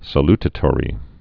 (sə-ltə-tôrē)